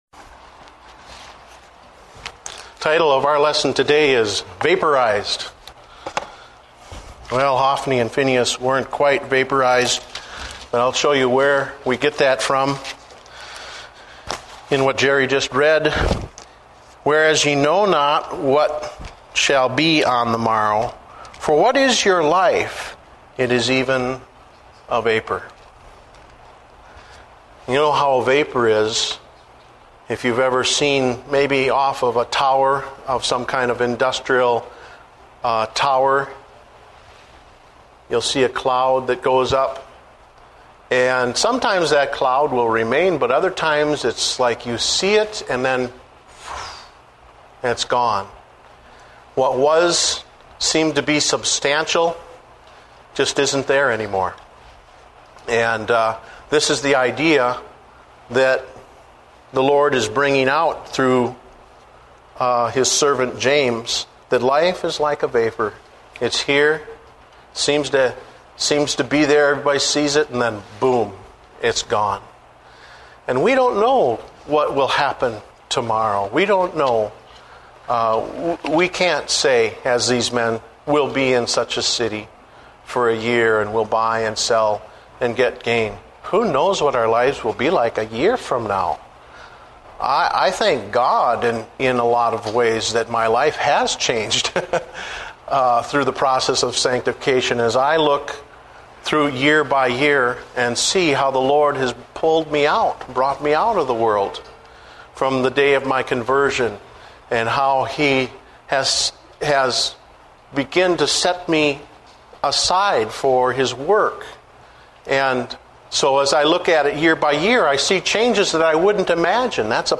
Date: July 13, 2008 (Adult Sunday School)